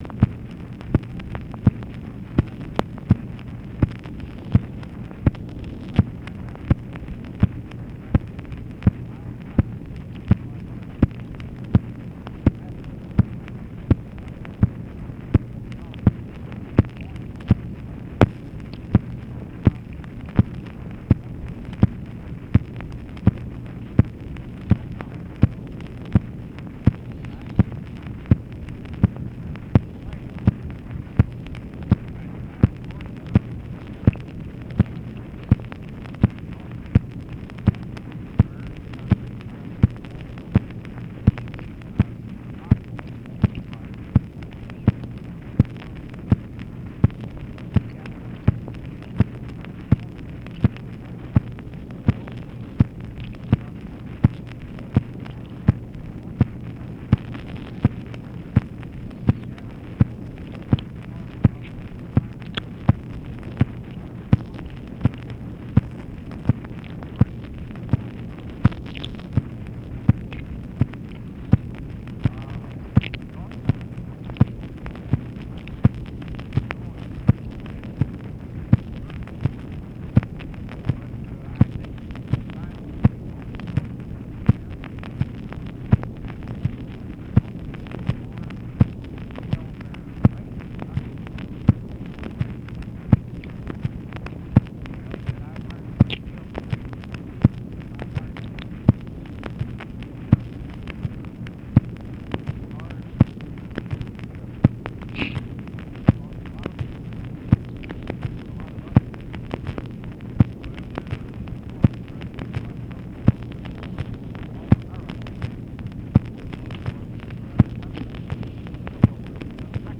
Conversation with ROBERT ANDERSON, December 29, 1964
Secret White House Tapes